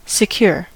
secure: Wikimedia Commons US English Pronunciations
En-us-secure.WAV